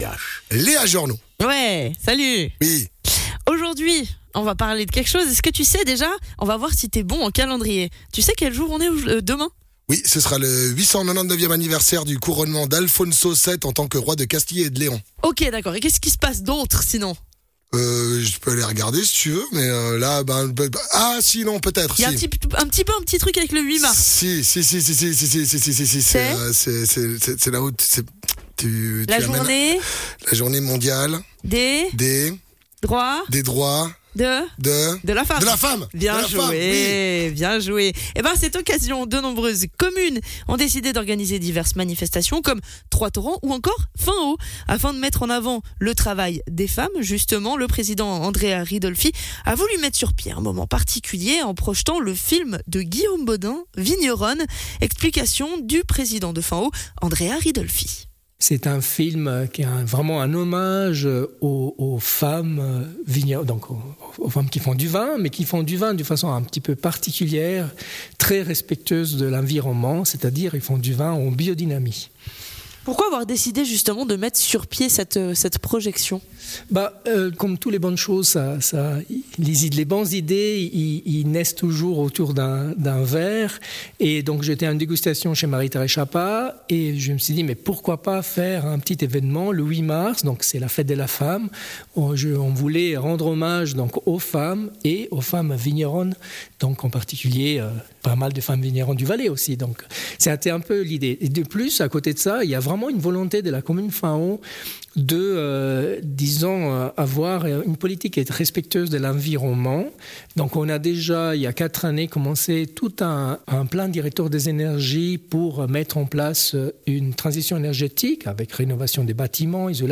Intervenant(e) : Andrea Ridolfi, président de Finhaut